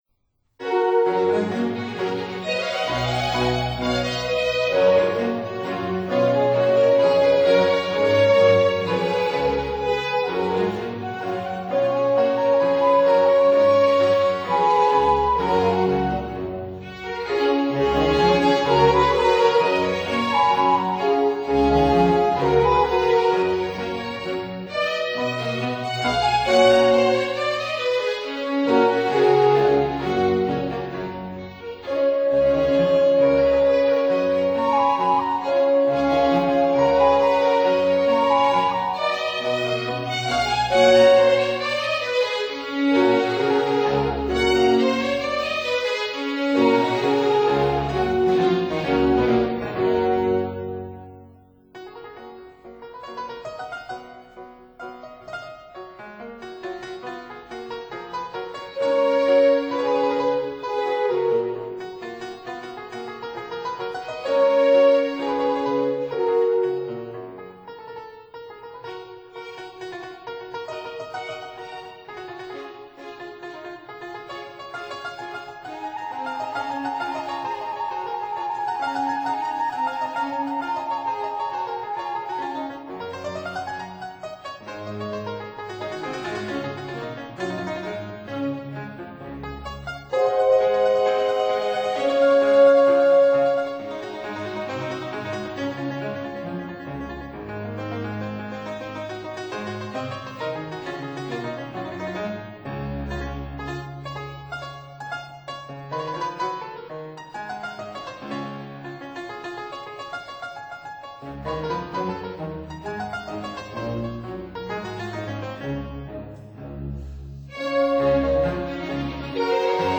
Fortepiano
(Period Instruments)